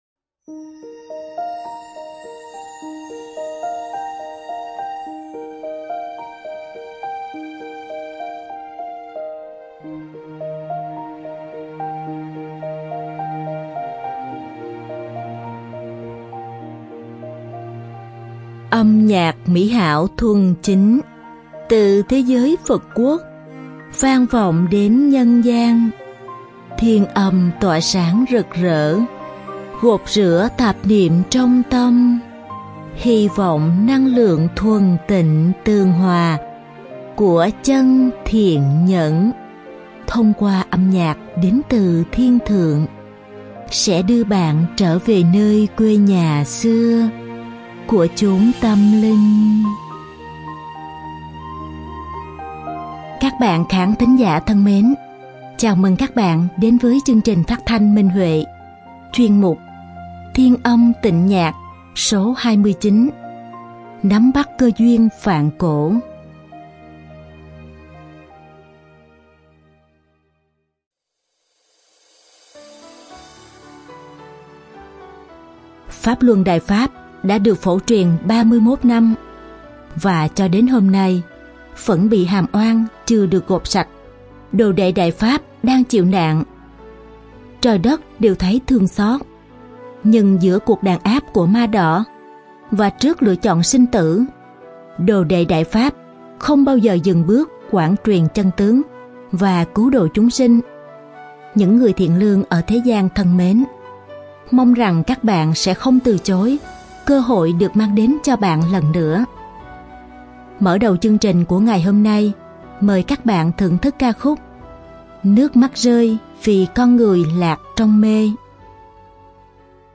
Đơn ca nữ
Đơn ca nam